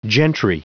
Prononciation du mot gentry en anglais (fichier audio)
Prononciation du mot : gentry